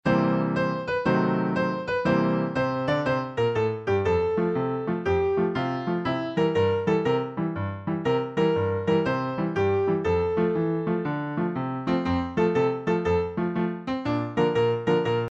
Children's Song Lyrics and Sound Clip
Folk Song Lyrics